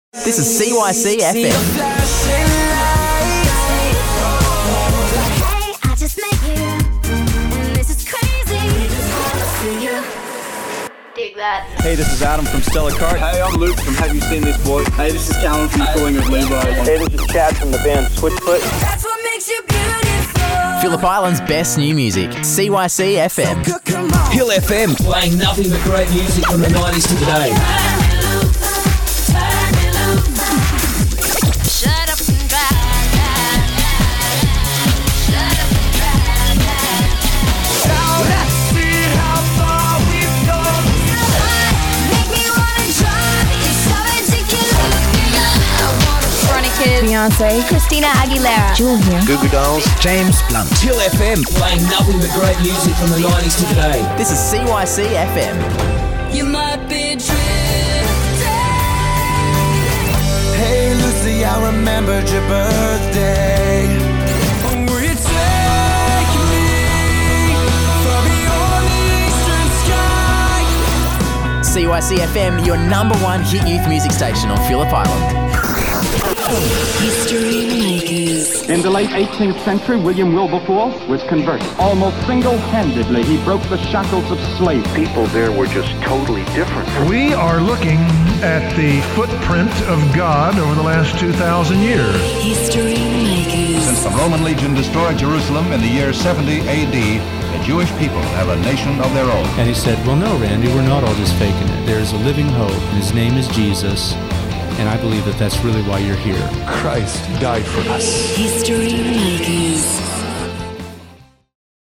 Listen Now Listen to a quick production demo
quick_portfolio_mixdown_2014.mp3